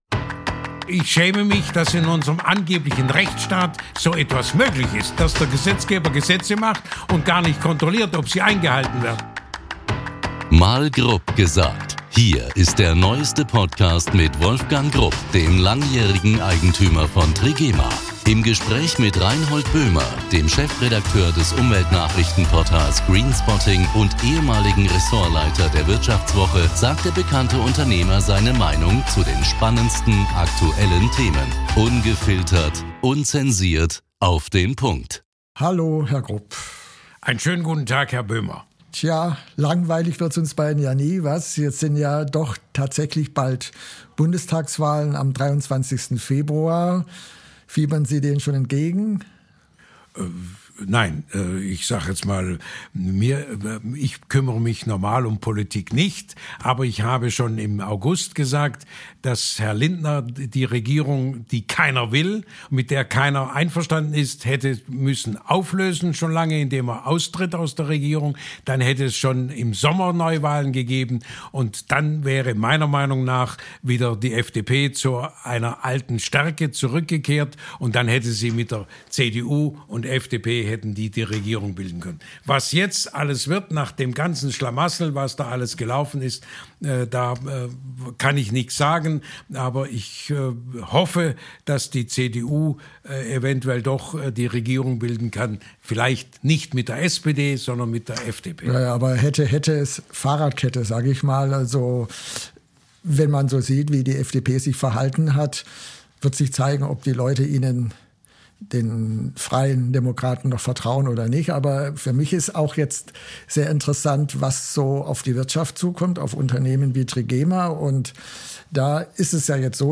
In gewohnter Manier schildert der Textilpionier seine Meinung zu aktuellen Ereignissen aus Gesellschaft, Kultur, Politik und Wirtschaft – ganz unverblümt, ehrlich und direkt, wie man ihn kennt